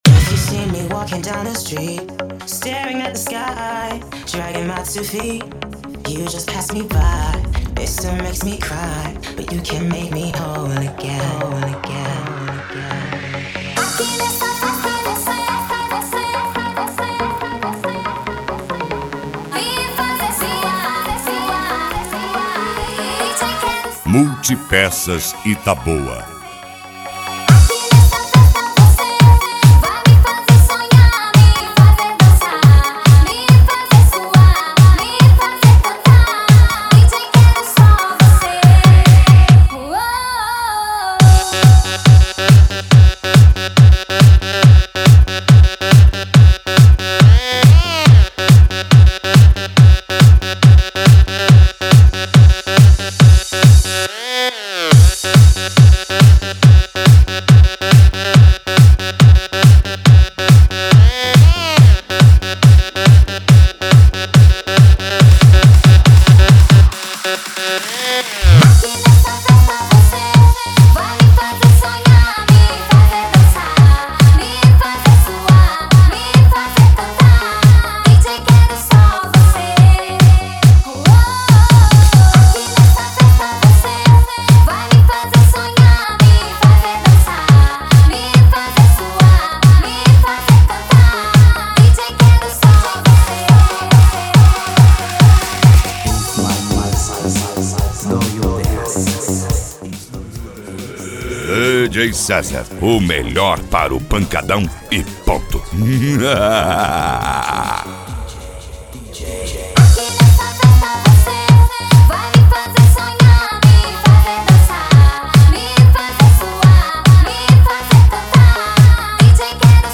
PANCADÃO